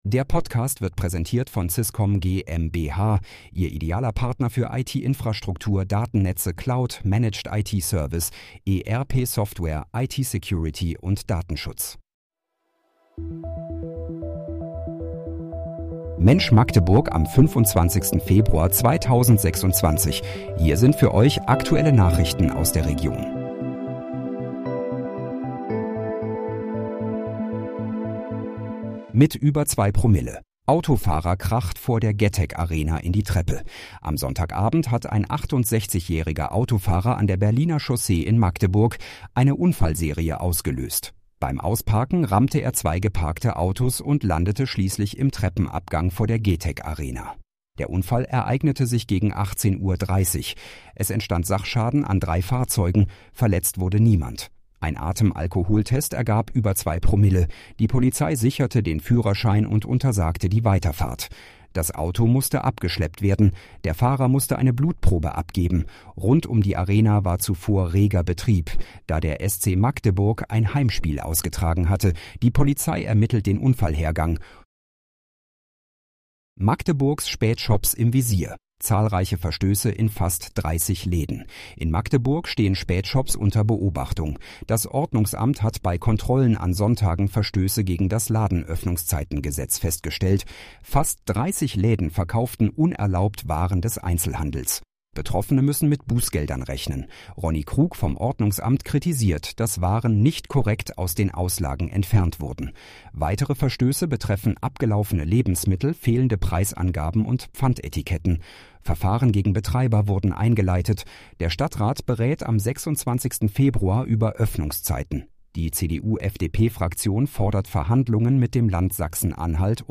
Mensch, Magdeburg: Aktuelle Nachrichten vom 25.02.2026, erstellt mit KI-Unterstützung